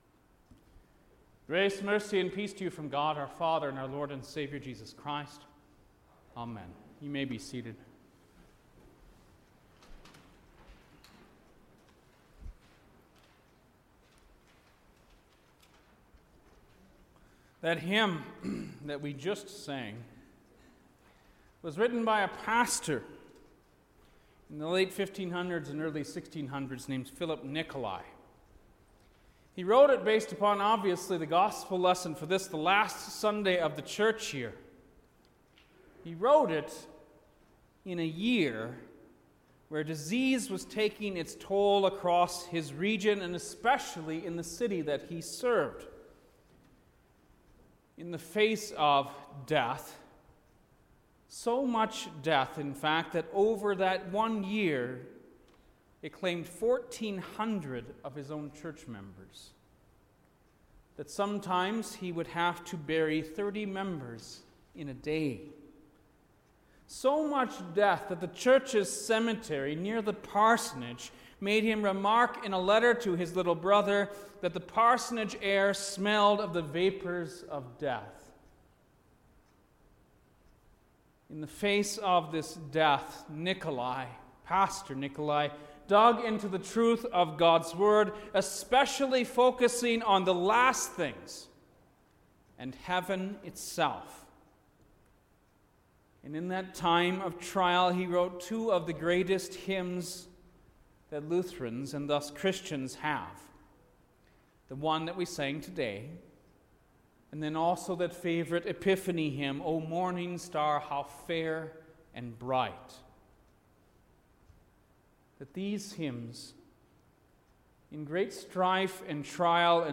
November-22-Last-Day-of-the-Church-Year_Sermon.mp3